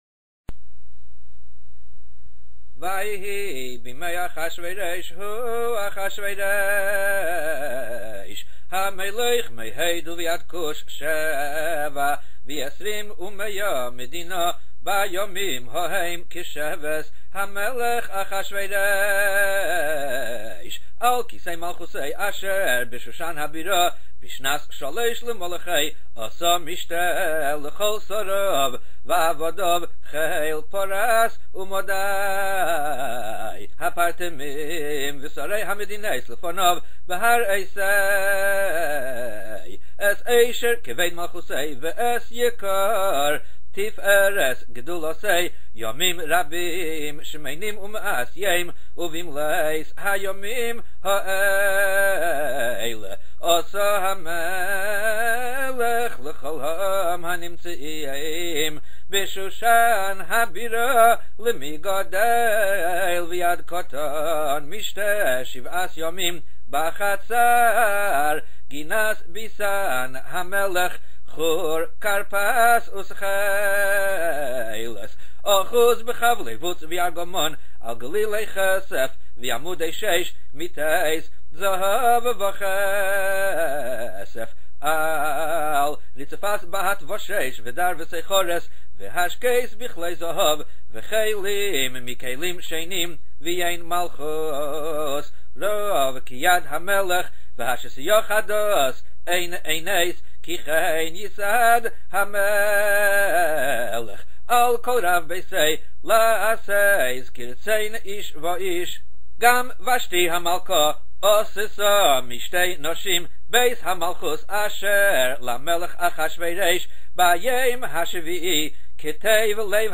קריאת_המגילה.mp3